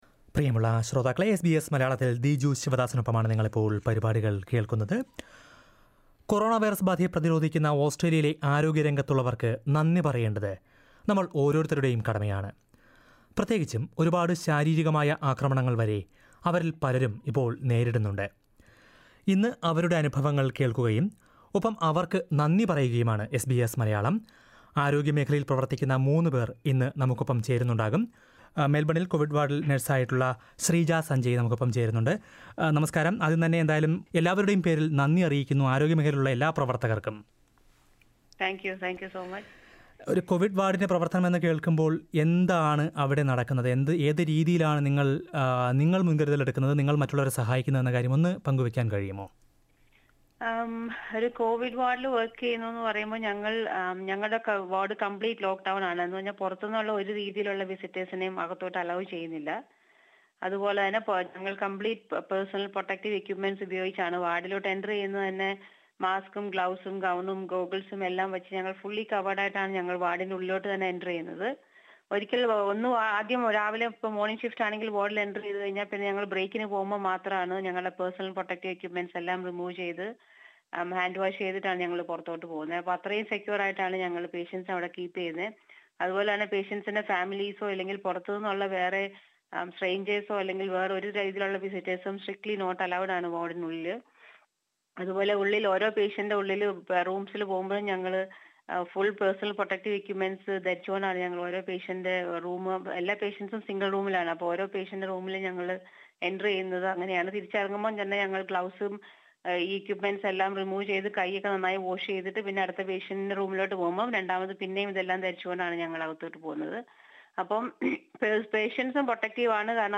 Listen to a few frontline healthcare workers who are fighting the coronavirus pandemic in Australia...